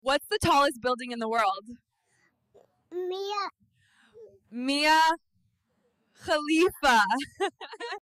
Hyper-realistic AI baby interview